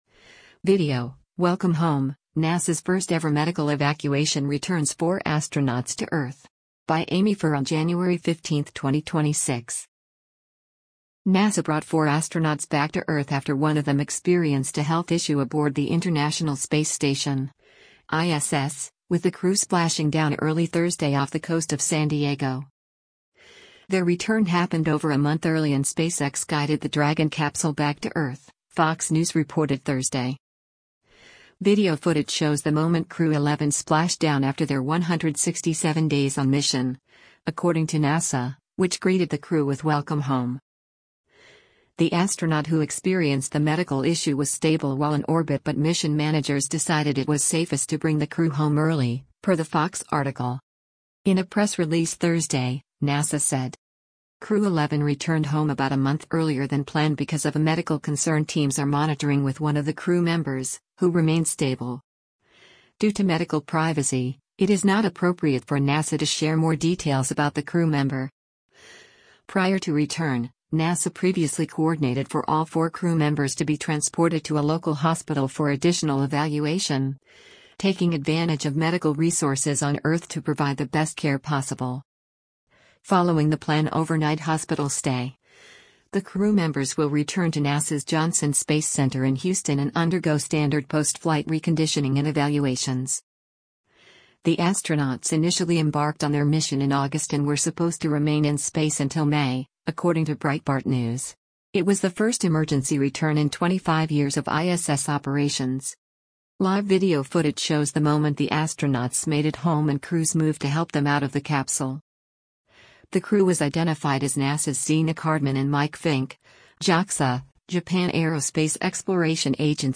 Video footage shows the moment Crew-11 splashed down after their 167 days on mission, according to NASA, which greeted the crew with “Welcome home”: